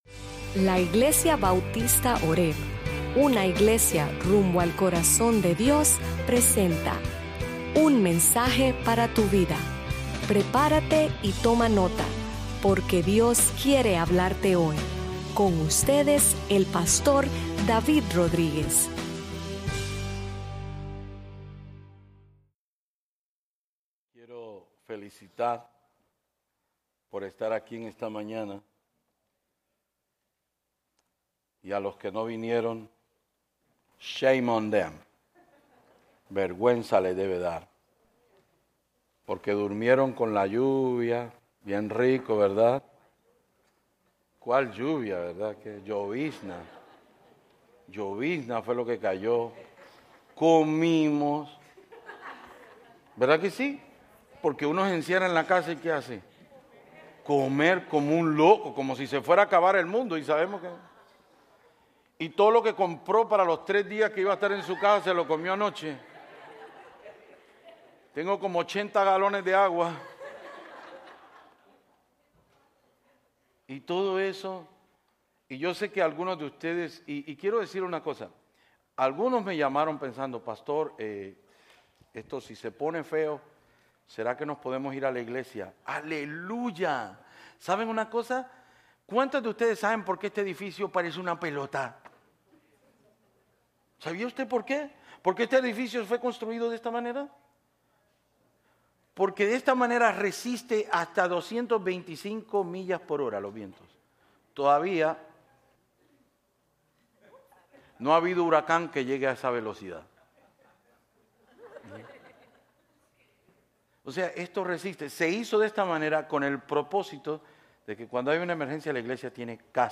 Sermons Archive - Page 108 of 156 - horebnola-New Orleans, LA